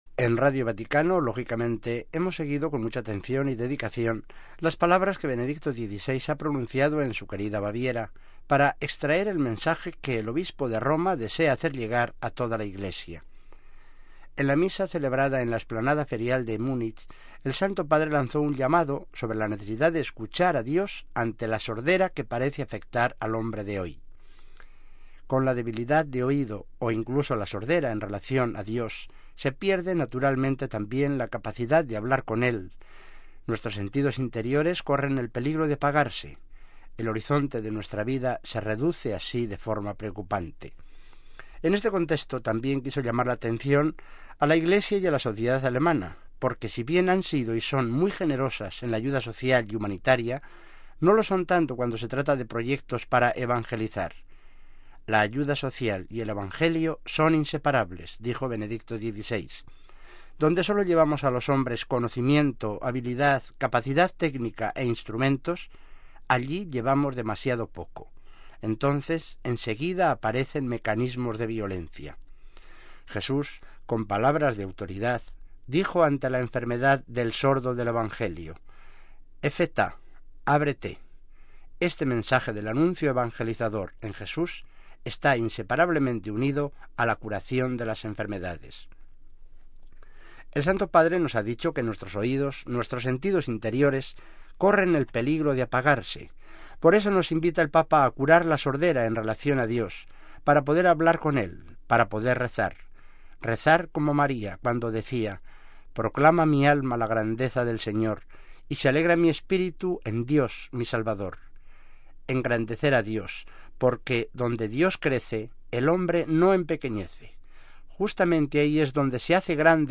Reflexión sobre el viaje del Papa a su Baviera natal